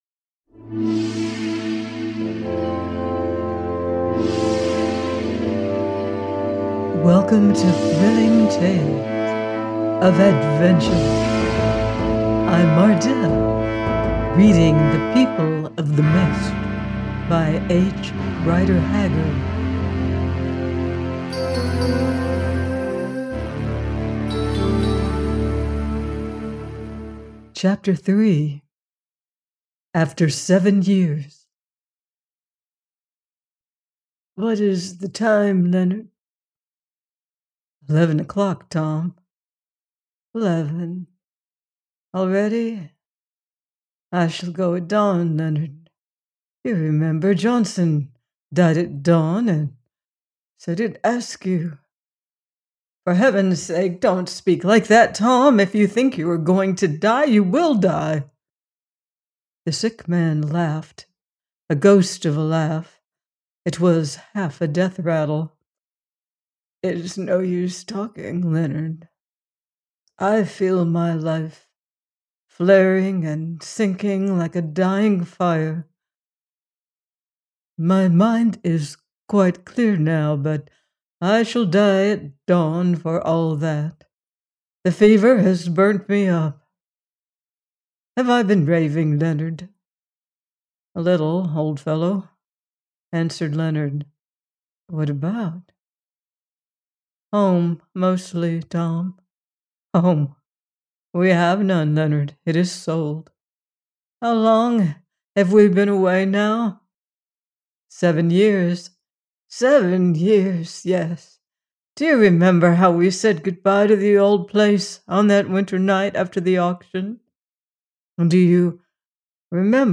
The People of the Mist – 3 : by H. Rider Haggard - audiobook